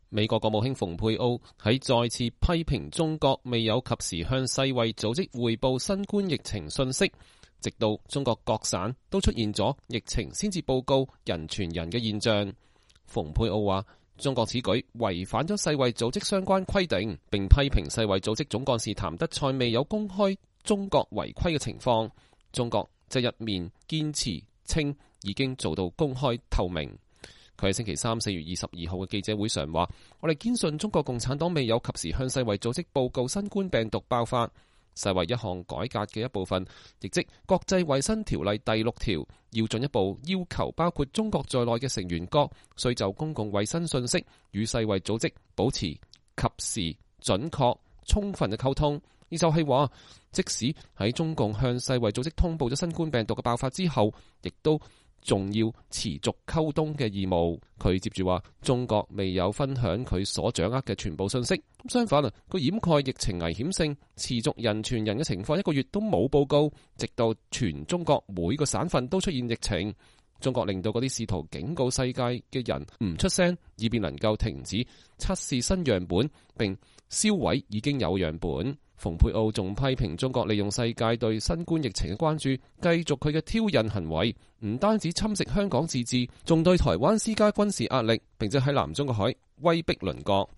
國務卿蓬佩奧在國務院發表講話。（2020年4月22日）